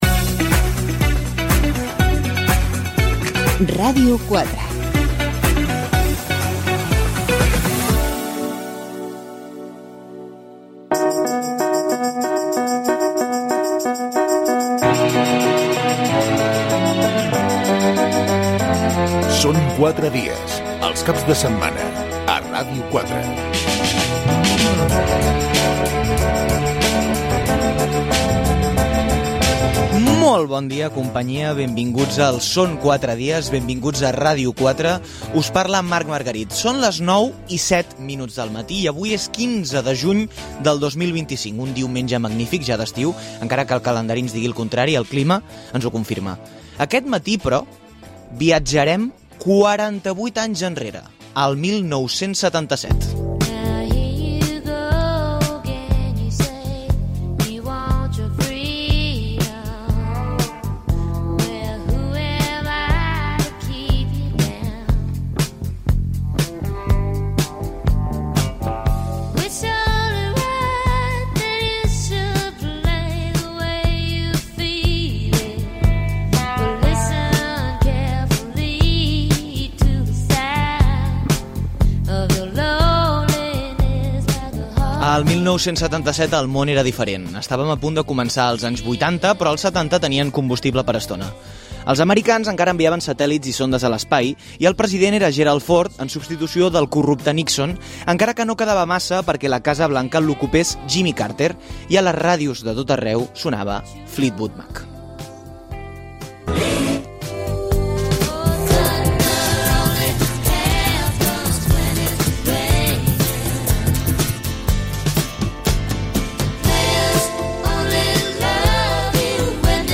Careta del programa, benvinguda, data, l'any 1977, sumari de continguts, indicatiu del programa, tertúlia de futbol